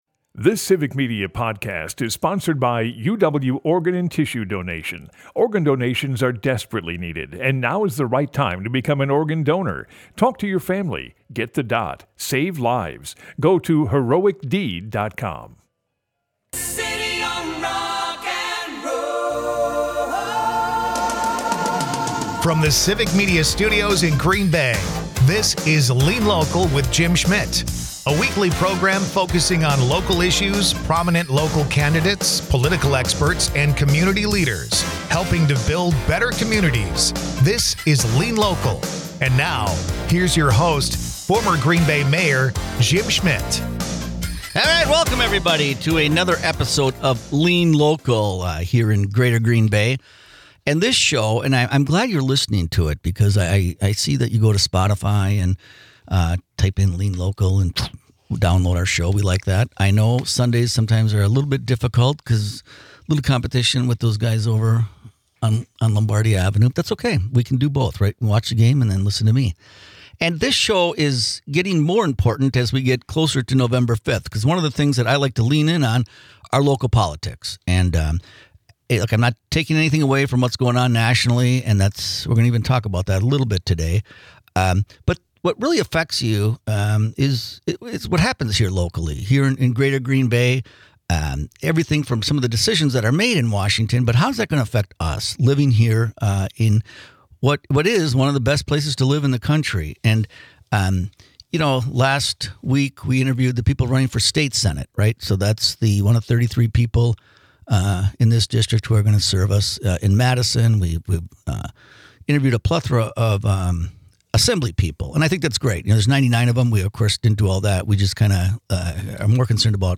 Former Green Bay Mayor, Jim Schmitt is joined by runner for WI Congressional District 8, Tony Wied. Jim & Tony talks about how expensive it is to run for a primary, what inspired Tony to run, and Tony's priorities.